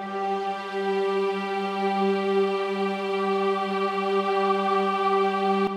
Orchestra
g5.wav